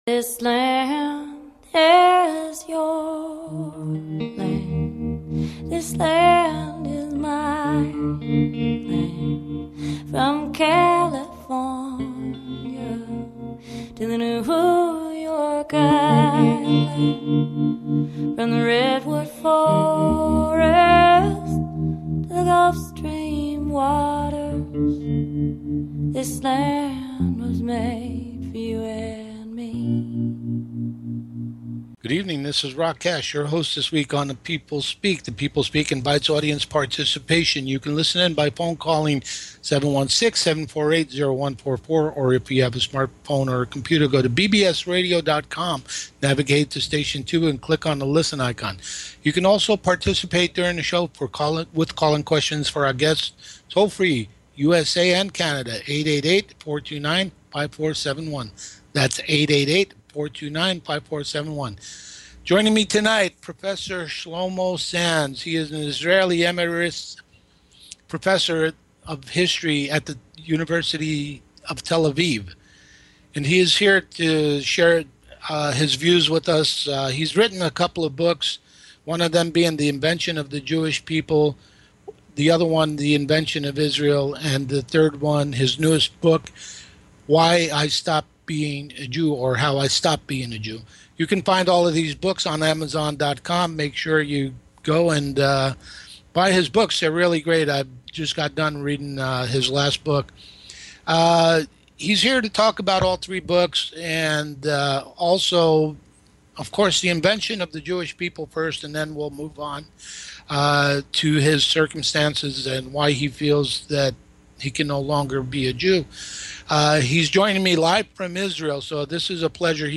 Guest Shlomo Sand